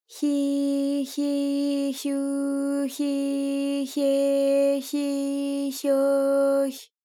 ALYS-DB-001-JPN - First Japanese UTAU vocal library of ALYS.
hyi_hyi_hyu_hyi_hye_hyi_hyo_hy.wav